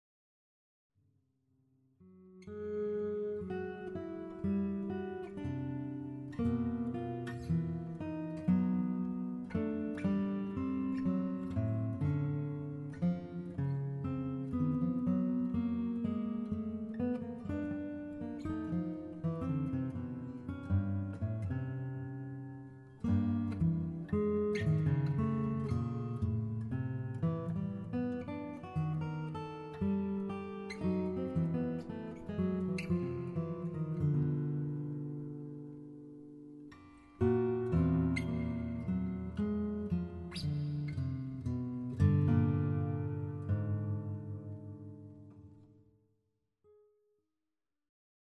for two guitars